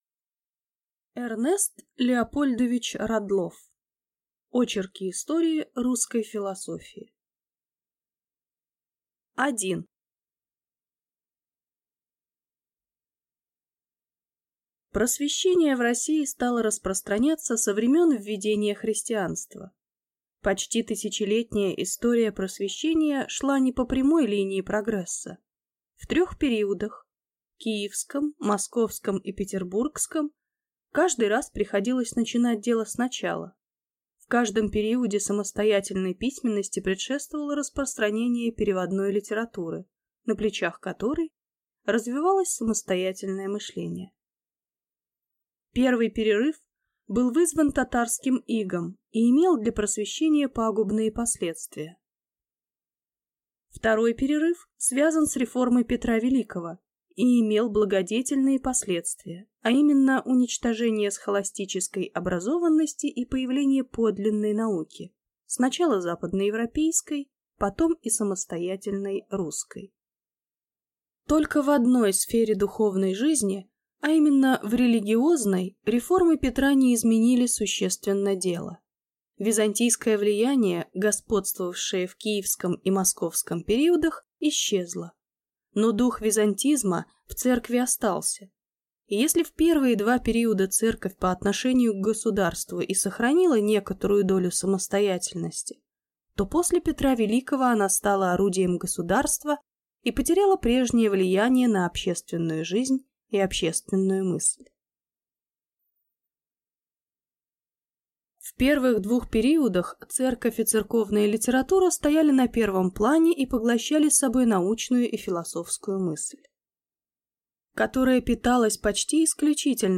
Аудиокнига Очерки истории русской философии | Библиотека аудиокниг